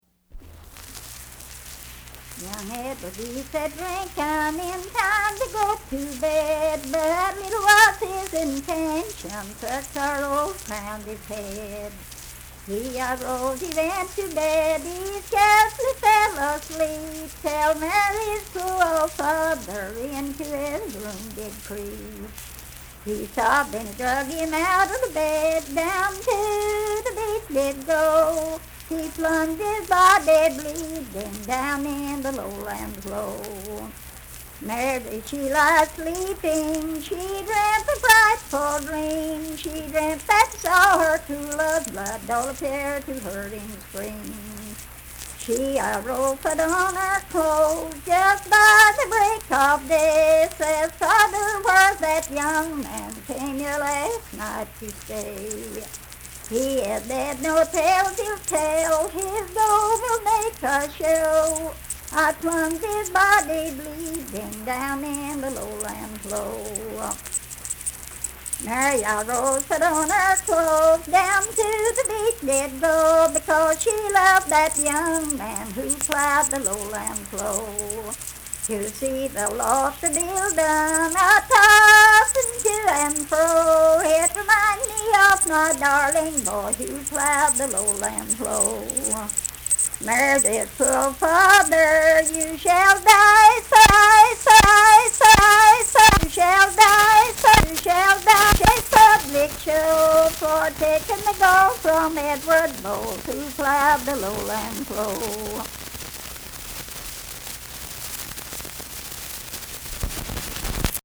Unaccompanied vocal music
Verse-refrain 5(8).
Voice (sung)
Logan County (W. Va.)